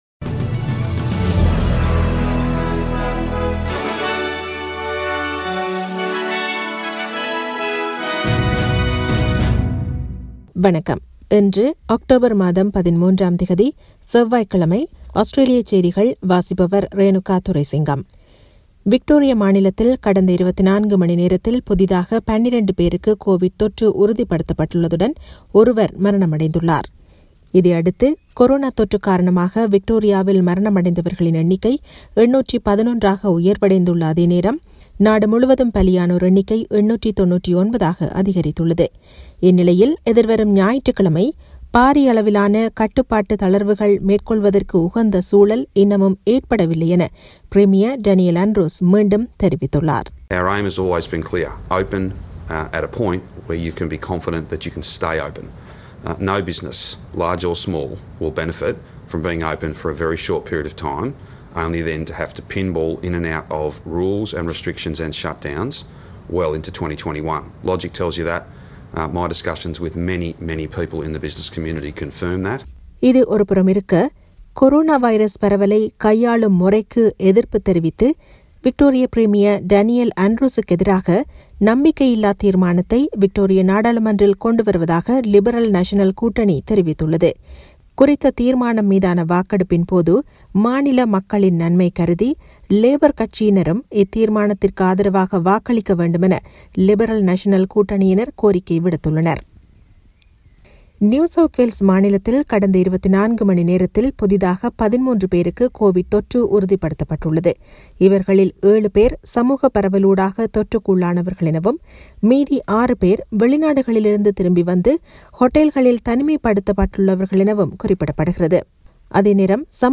Australian news bulletin for Tuesday 13 October 2020.